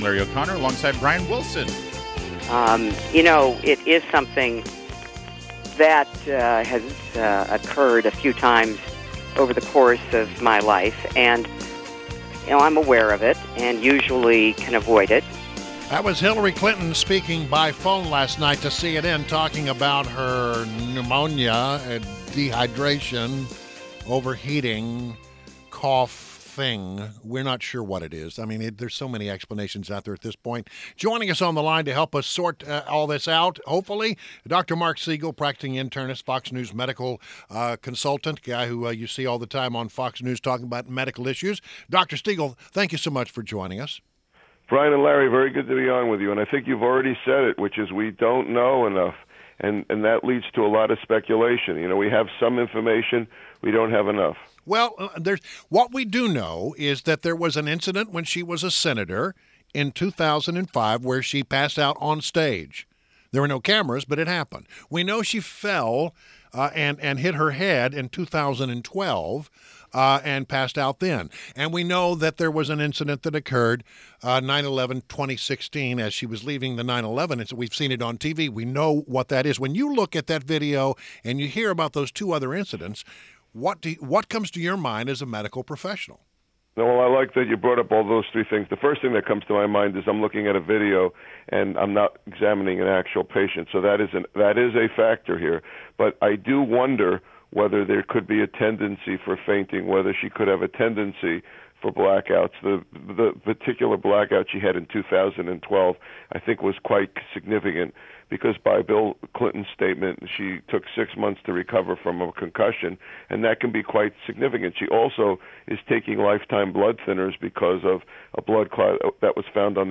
WMAL Interview - MARC SIEGEL - 09.13.16